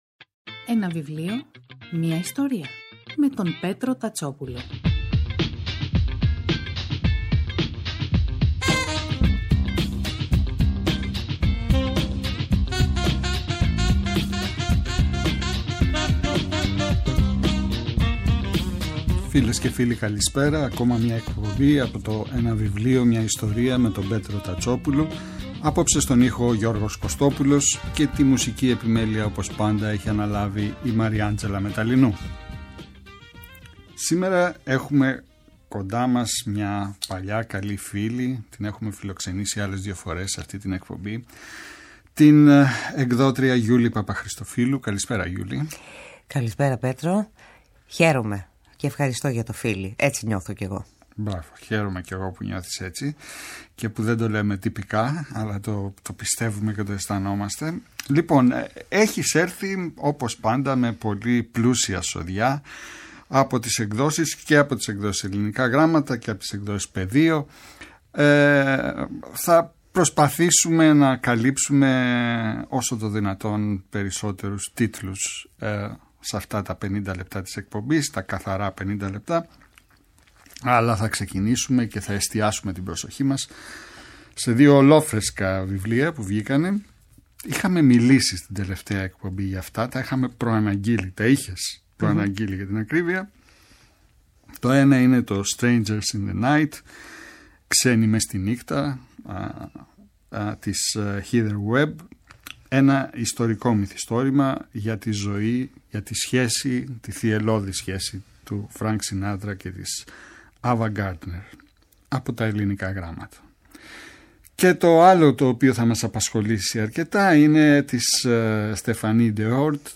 Κάθε Σάββατο και Κυριακή, στις 5 το απόγευμα στο Πρώτο Πρόγραμμα της Ελληνικής Ραδιοφωνίας ο Πέτρος Τατσόπουλος , παρουσιάζει ένα συγγραφικό έργο, με έμφαση στην τρέχουσα εκδοτική παραγωγή, αλλά και παλαιότερες εκδόσεις. Η γκάμα των ειδών ευρύτατη, από μυθιστορήματα και ιστορικά μυθιστορήματα, μέχρι βιογραφίες, αυτοβιογραφίες και δοκίμια.